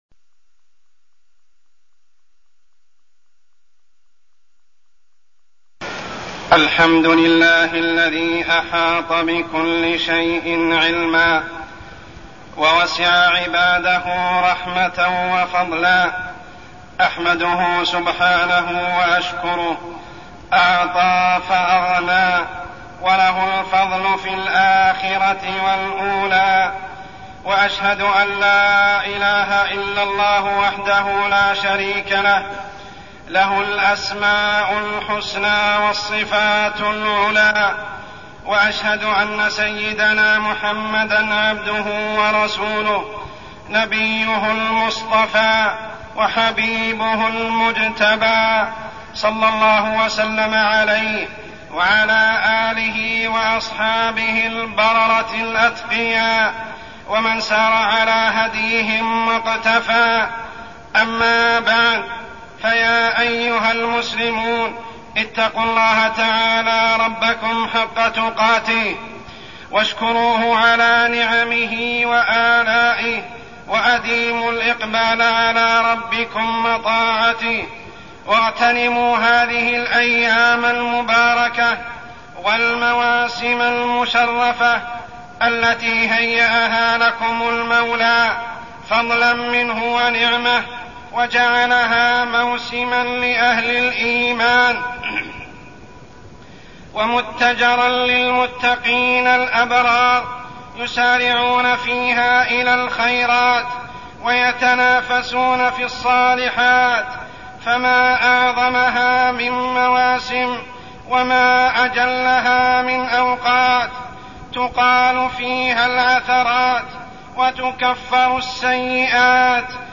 تاريخ النشر ٢٠ رمضان ١٤١٦ هـ المكان: المسجد الحرام الشيخ: عمر السبيل عمر السبيل فريضة الزكاة